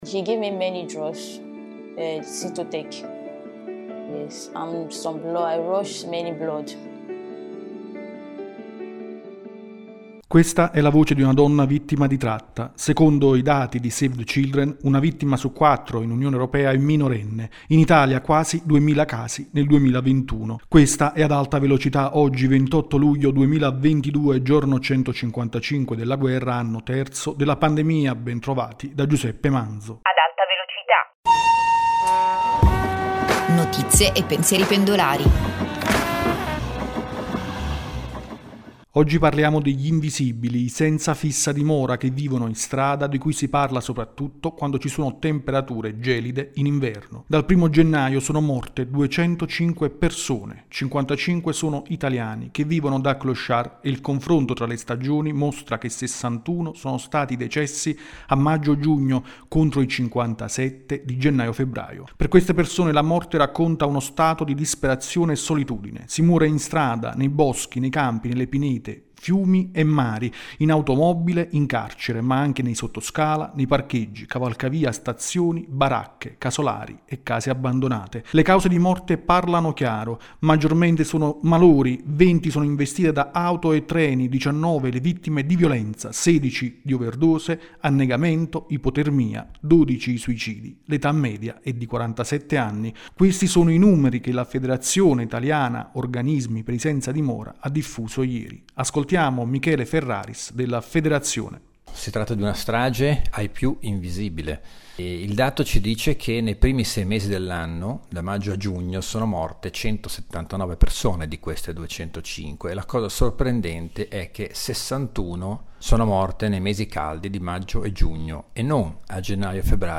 La voce di una donna vittima di tratta.